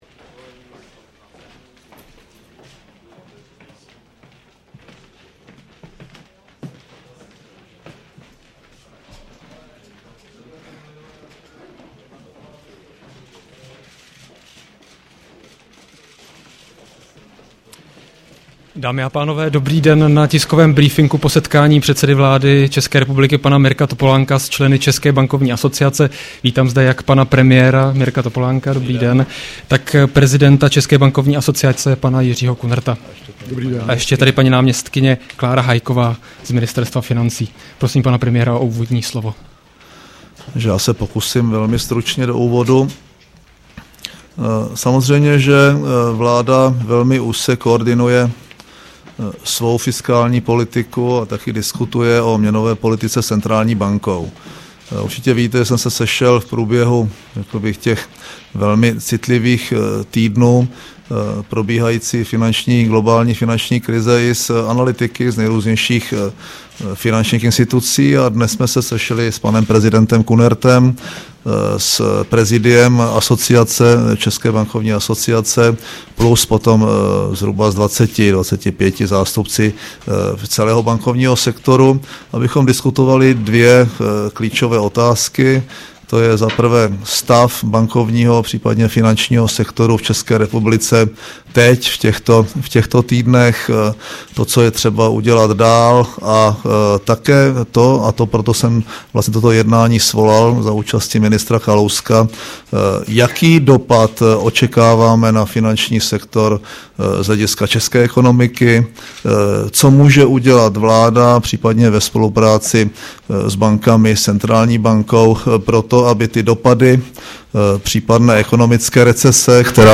Tisková konference premiéra M. Topolánka po setkání s představiteli České bankovní asociace 10.11.2008